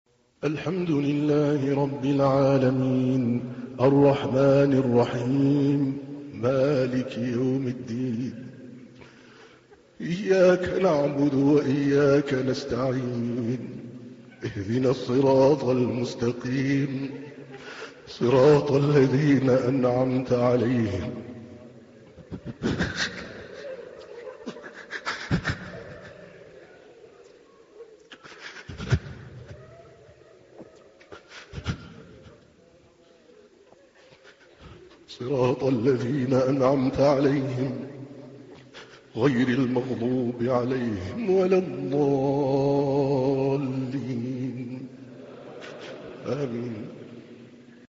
تحميل : 1. سورة الفاتحة / القارئ عادل الكلباني / القرآن الكريم / موقع يا حسين